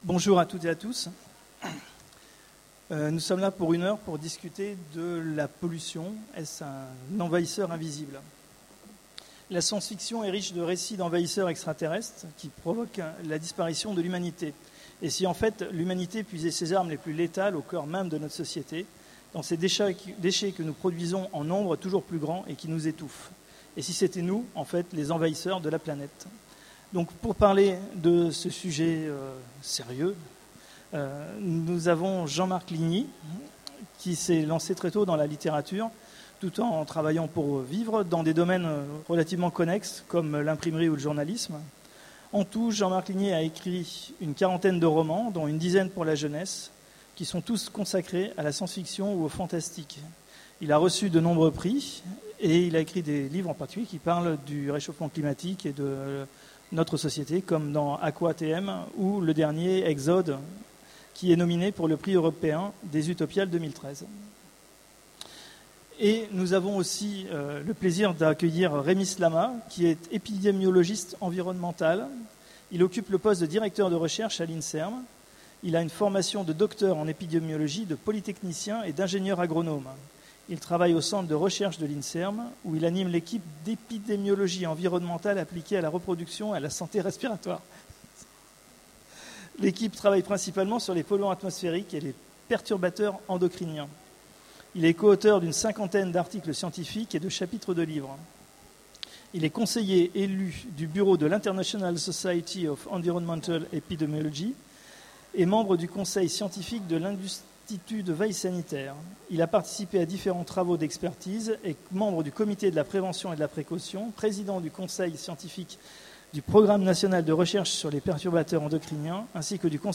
Utopiales 13 : Conférence La pollution : l'envahisseur invisible